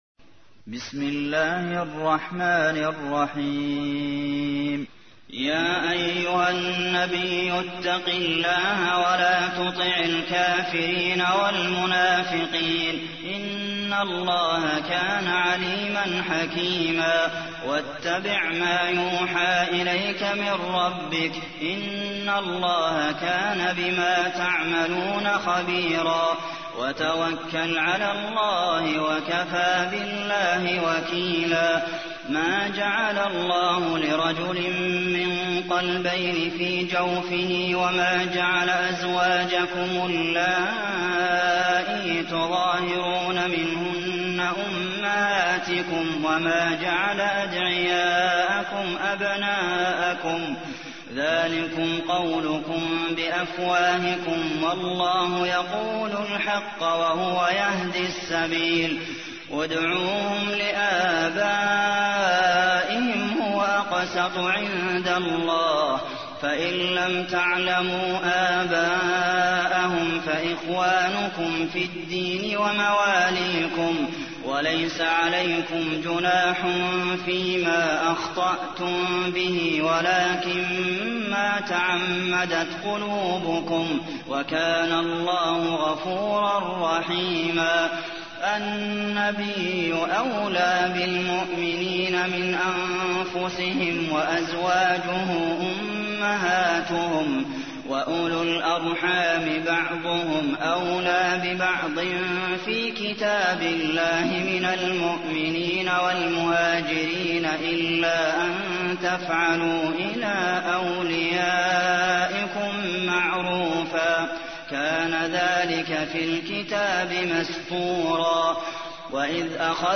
تحميل : 33. سورة الأحزاب / القارئ عبد المحسن قاسم / القرآن الكريم / موقع يا حسين